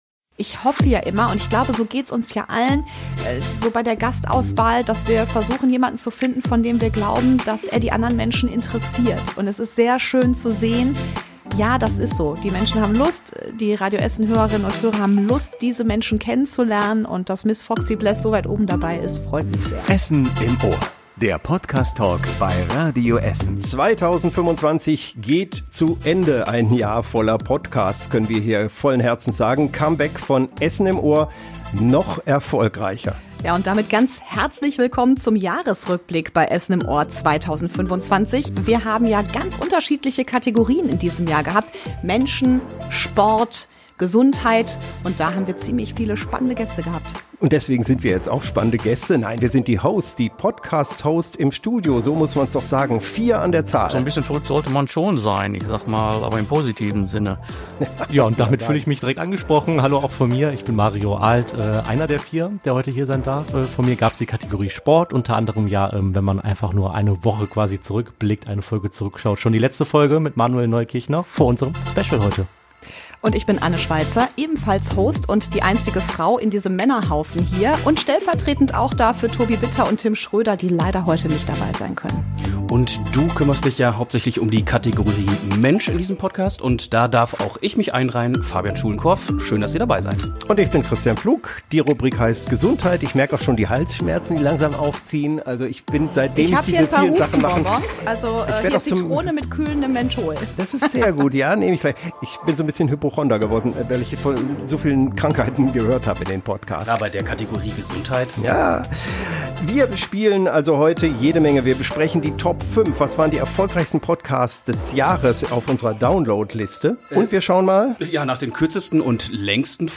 Im Radio Essen-Podcast "Essen im Ohr" waren auch 2025 wieder viele spannende Gäste im Gespräch. Menschen aus Sport und Gesundheit, Politik und Kultur haben dabei unseren Steckbrief ausgefüllt, Fragen aus dem Glas beantwortet und spannende, kritische aber auch lustige Fragen beantwortet.